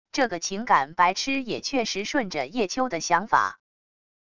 这个情感白痴也确实顺着叶秋的想法wav音频生成系统WAV Audio Player